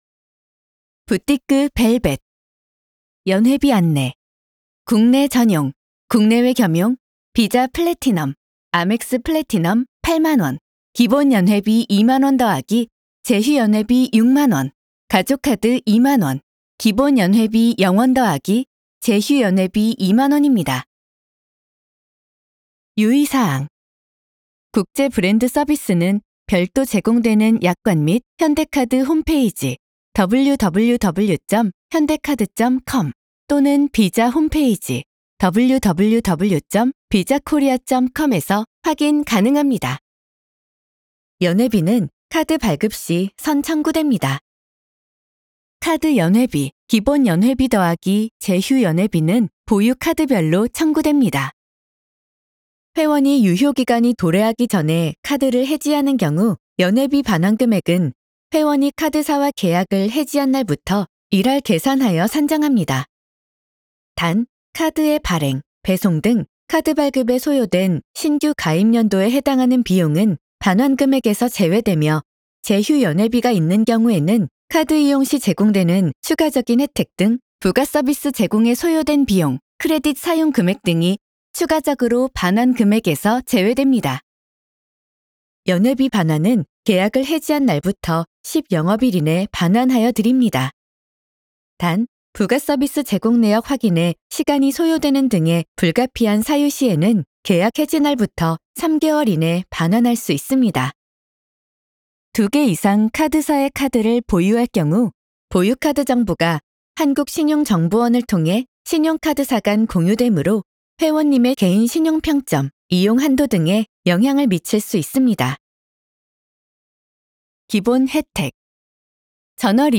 시각장애인 및 고령자를 위한 상품요약 안내 음성지원서비스
BTVV_VOICE_GUIDE.mp3